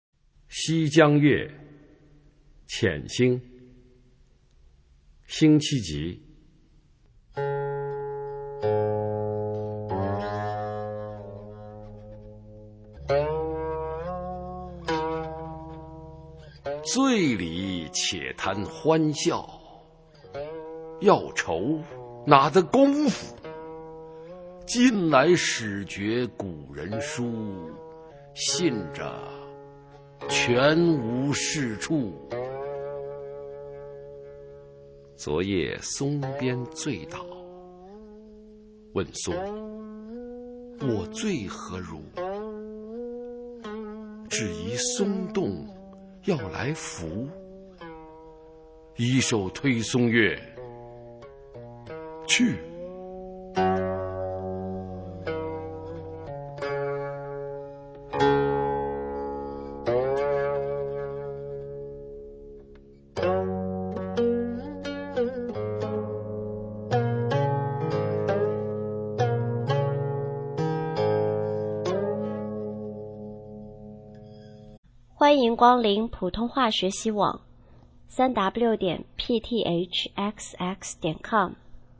普通话美声欣赏：西江月-遣兴　/ 佚名